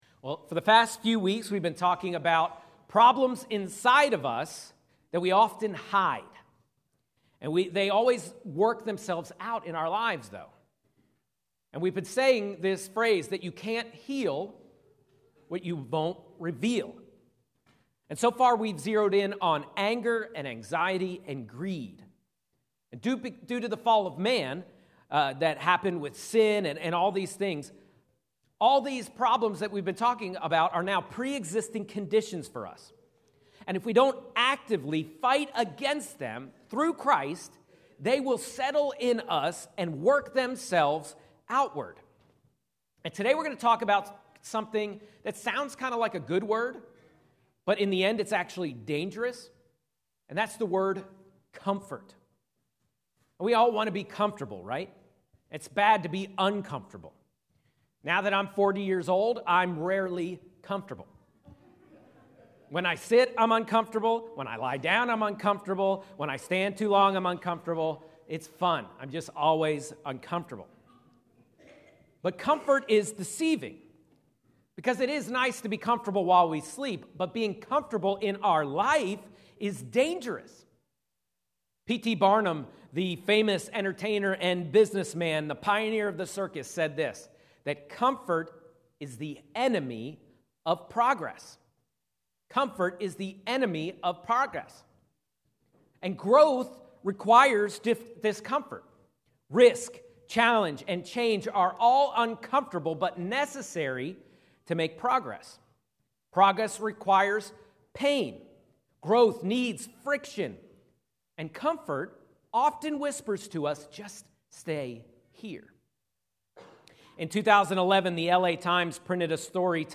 A message from the series "Inside Out." Jesus came to change us from the inside out.